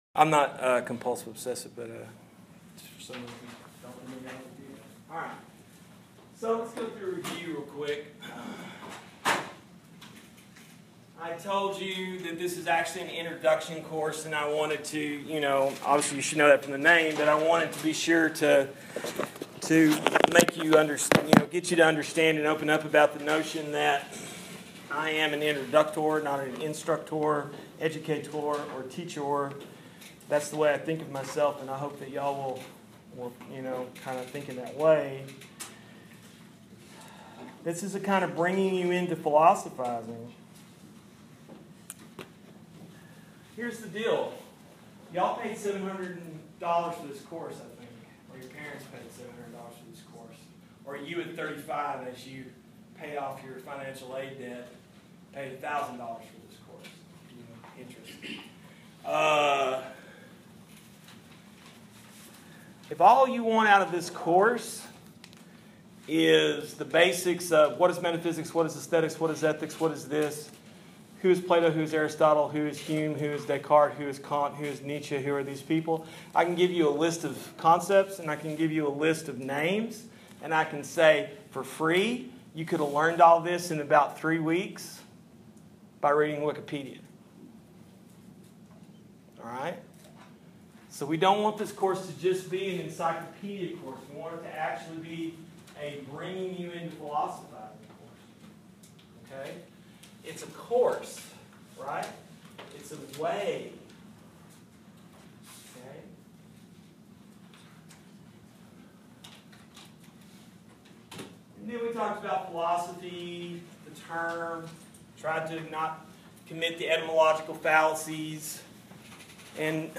M-W-F @ 1:00pm, lecture for 8/31/2016. The relationship of philosophizing (caring as skillfulness of mind) to freedom (the duty-to-be-friends) by way of mindful lineage.